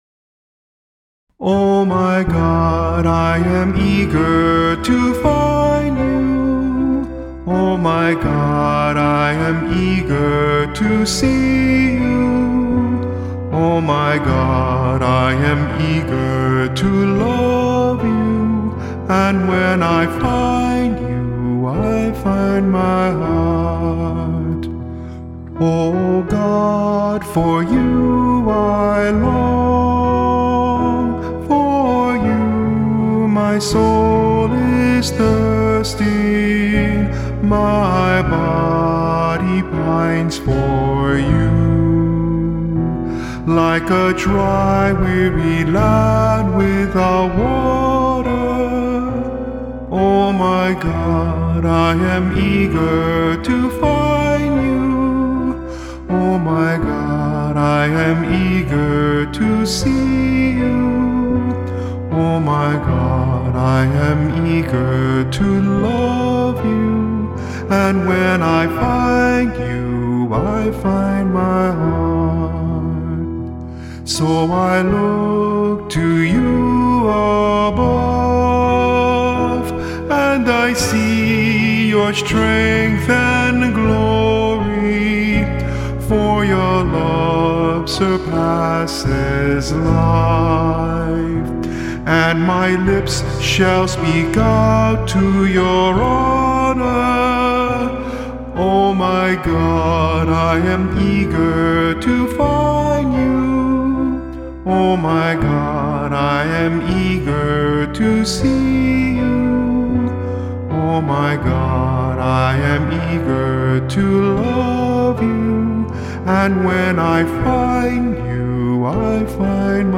Responsorial Psalms